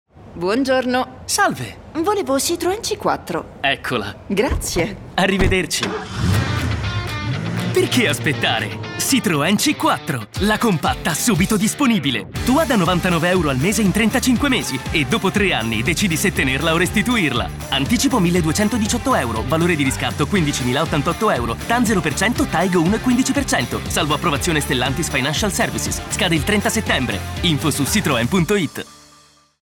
Ironico Veloce